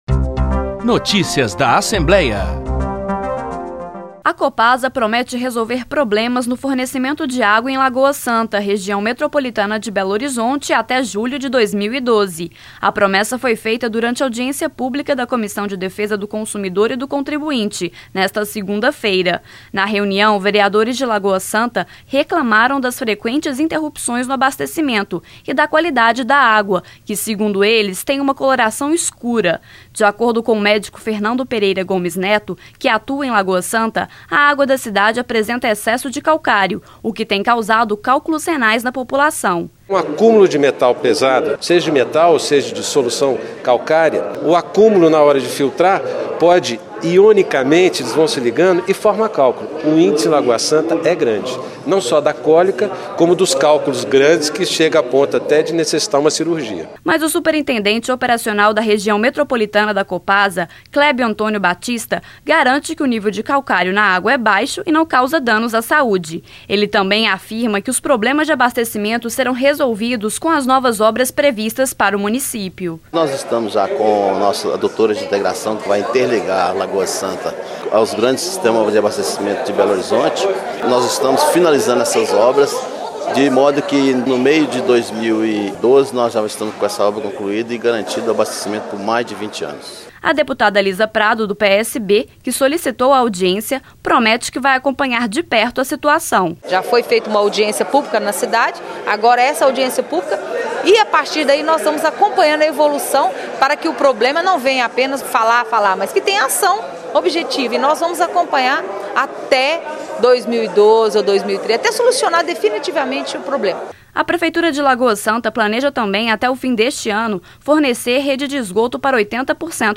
Durante audiência pública realizada pela Comissão de Defesa do Consumidor, a Copasa deu um prazo até julho de 2012 para resolver os problemas no abastecimento em Lagoa Santa, mas negou as denúncias de baixa qualidade da água.